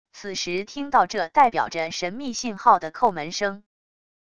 此时听到这代表着神秘信号的叩门声wav音频